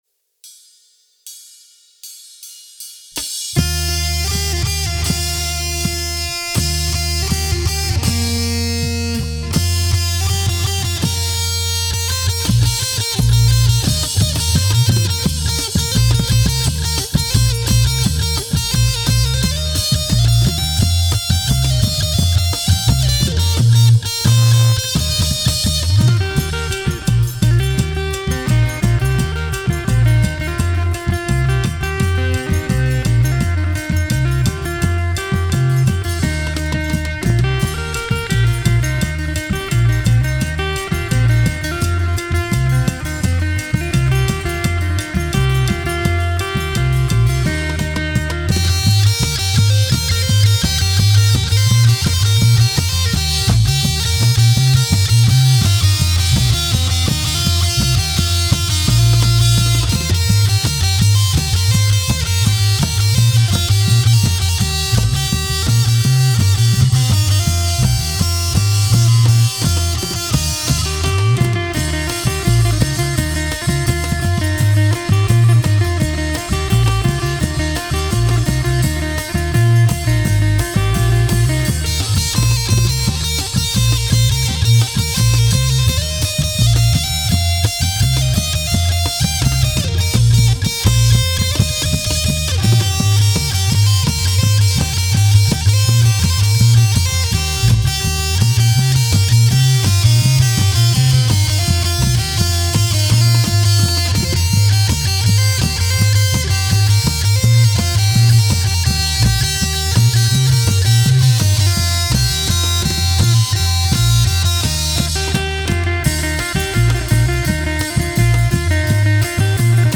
Genre: World, Tai